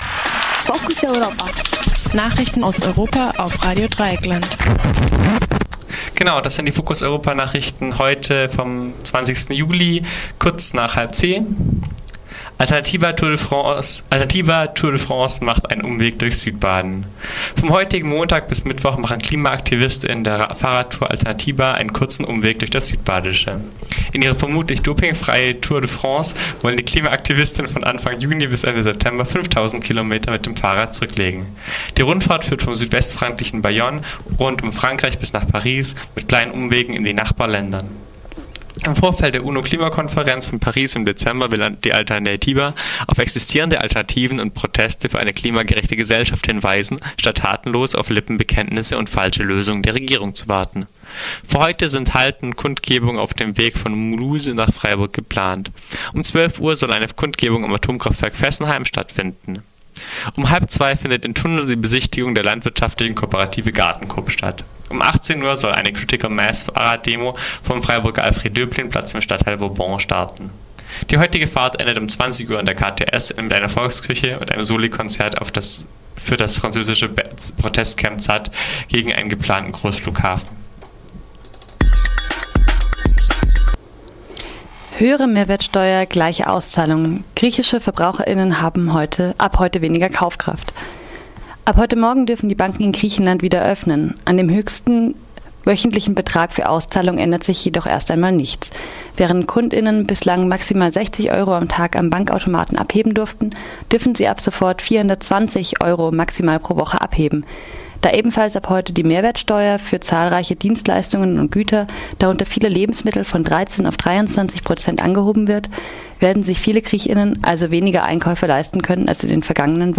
Focus Europa Nachrichten am Montag, 20. Juli 2015